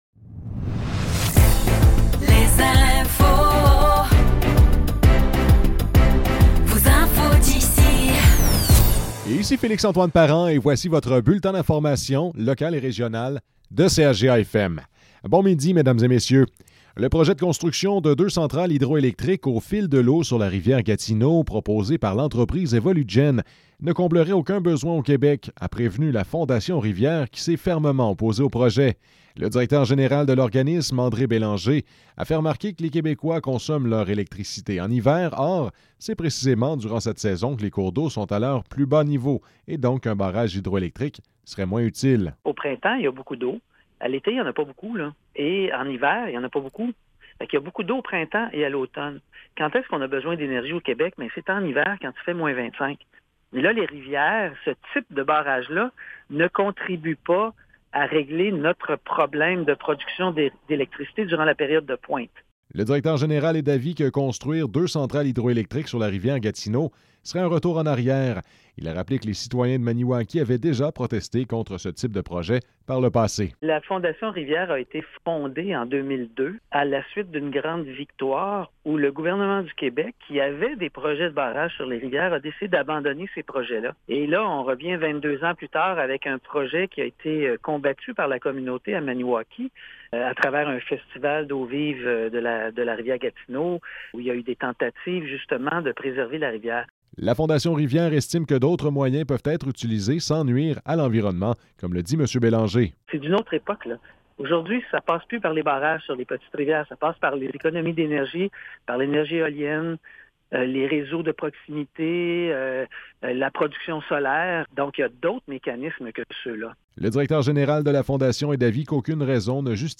Nouvelles locales - 26 mars 2024 - 12 h